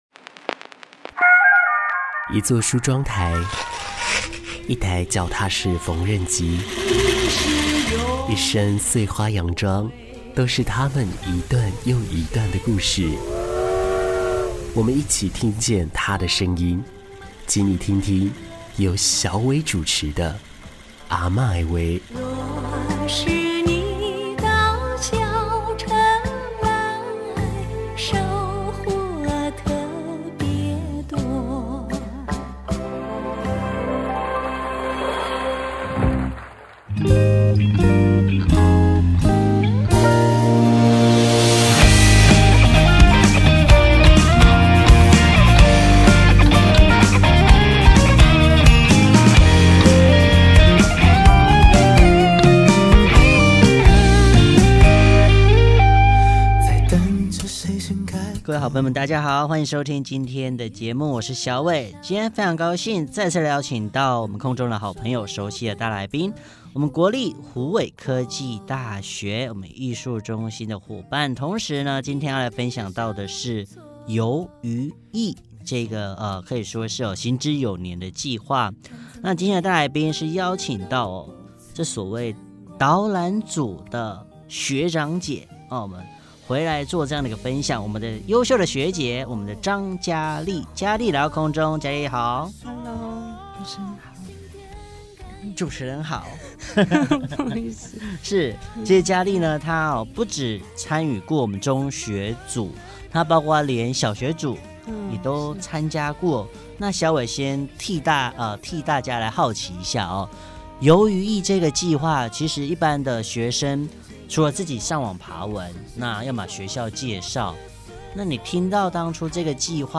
《阿嬤的話》用最貼近土地的聲音，記錄台灣各地的人情味，分享那些讓社會更加溫暖、更加正向的故事。